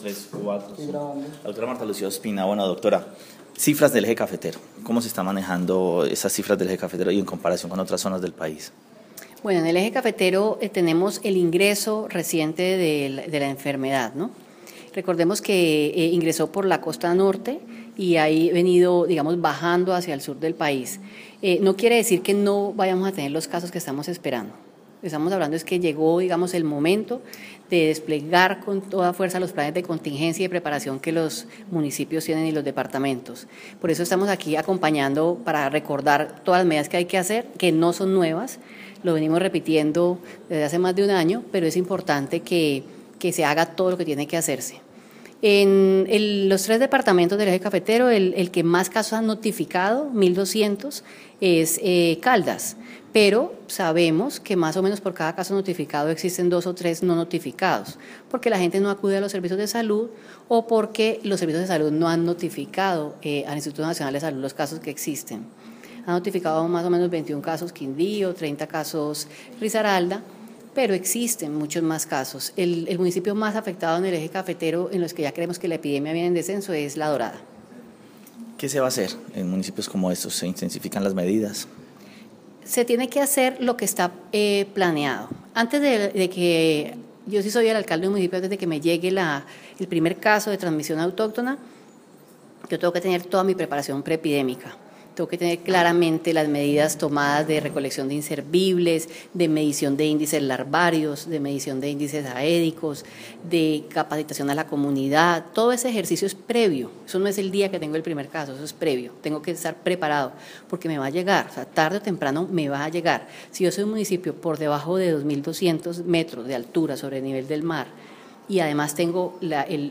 La Directora de Epidemiología y Demografía, Martha Lucía Ospina Martínez
Audio: Martha Lucia Ospina Martínez habla sobre efectos de chikunguña en el eje cafetero